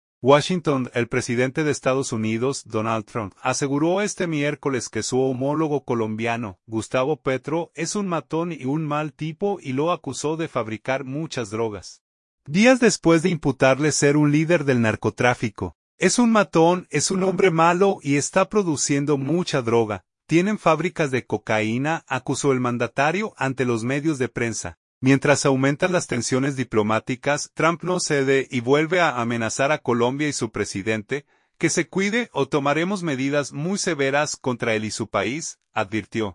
"Es un matón, es un hombre malo y está produciendo mucha droga. Tienen fábricas de cocaína", acusó el mandatario ante los medios de prensa.